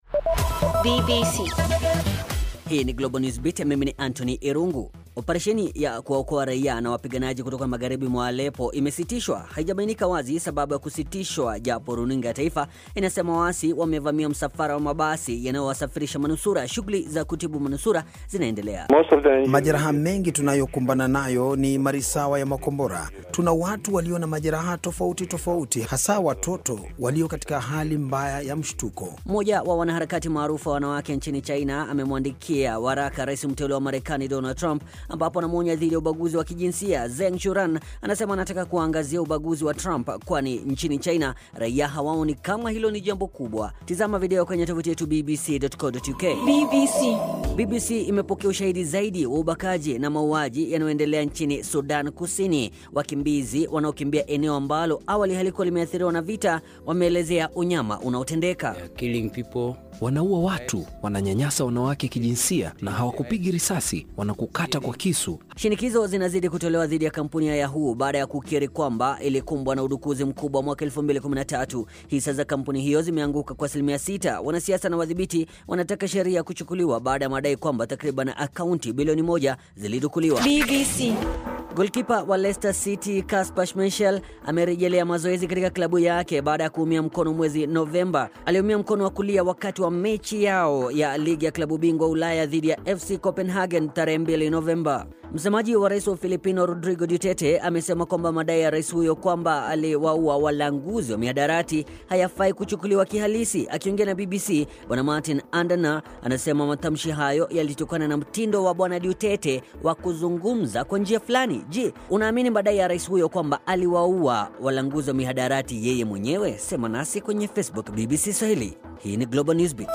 Mkimbizi asimulia unyama unaotekelezwa Sudan kusini. Mapigano nchini humo yatasitishwa vipi?